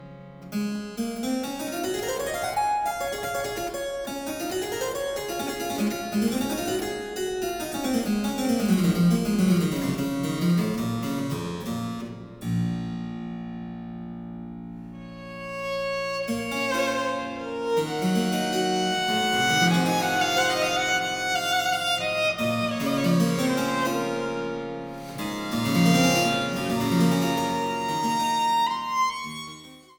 Die wohl bekanntesten Violinsonaten des 18. Jahrhunderts